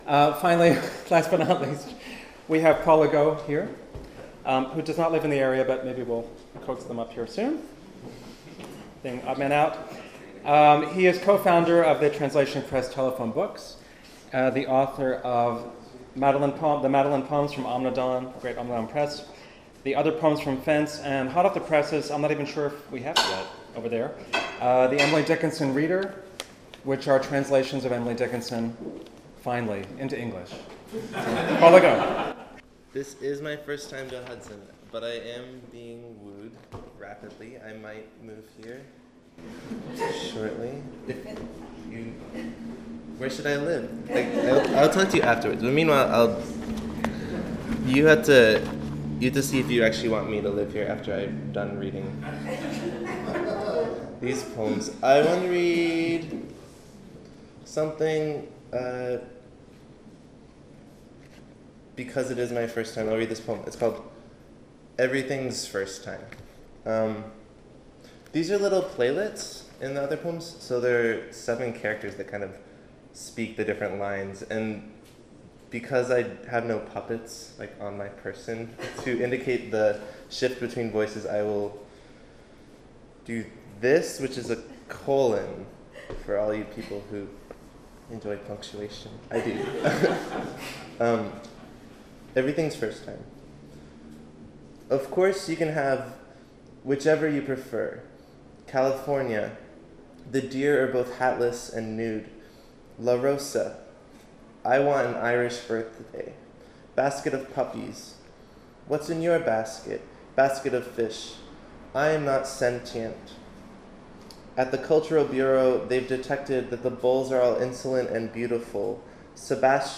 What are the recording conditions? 7th Annual Hudson Valley Literary Festival: May 19, 2012: 11am- 4pm Hudson Hall at the Historic Hudson Opera House